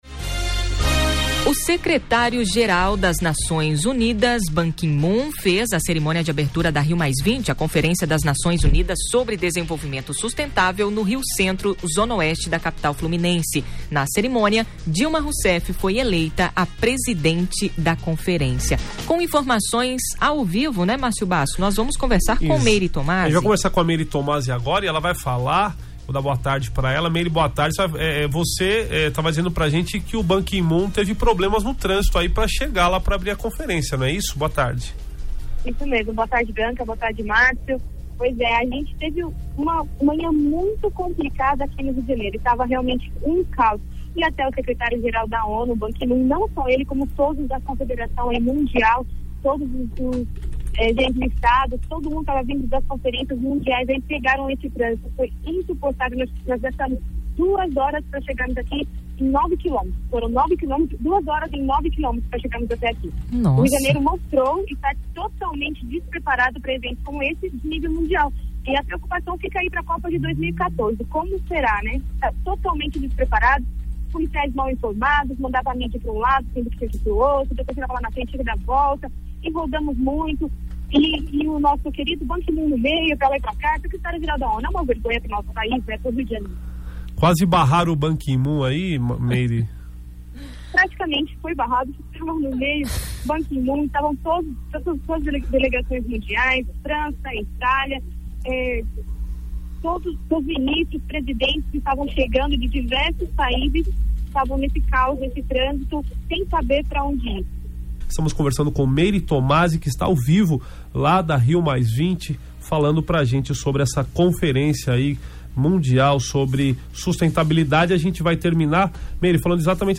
Com seriedade, dinamismo e buscando informações além da notícia, o Conexão Novo Tempo abordou este assunto nesta quarta-feira.